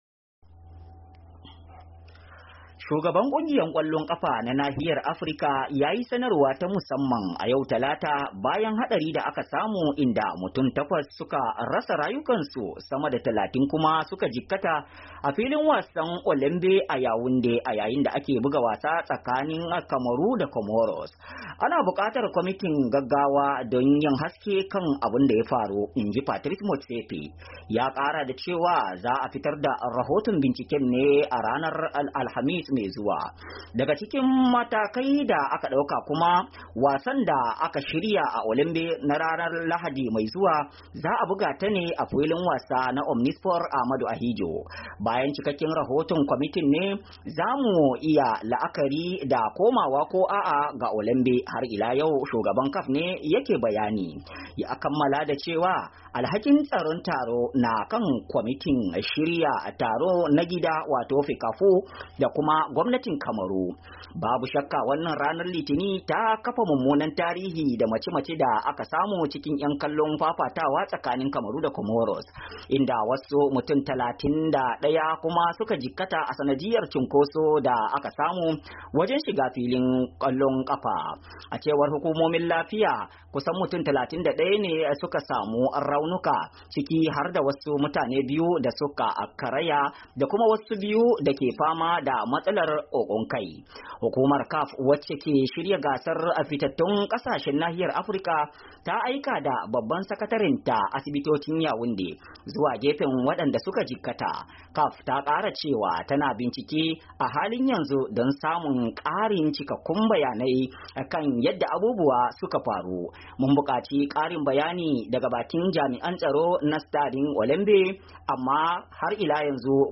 Saurari rahoto cikin sauti